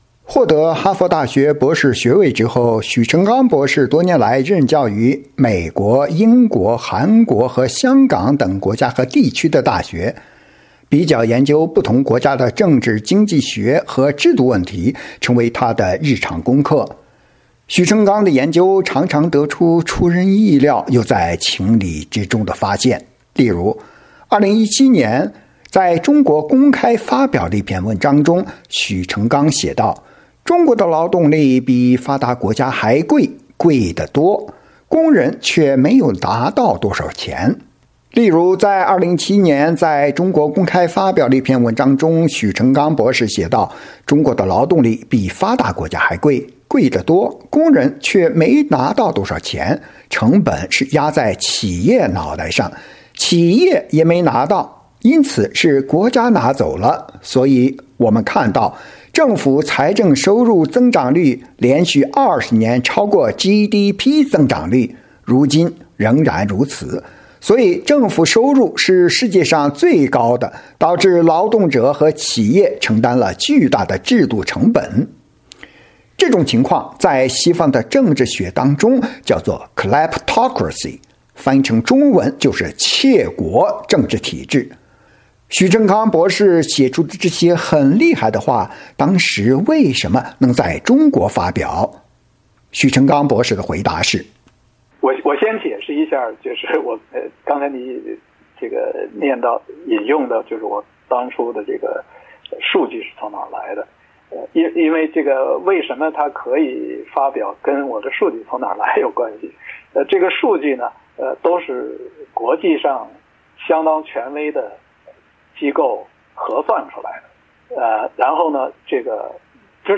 专访许成钢博士（3）：谈严峻的制度和严峻的教育问题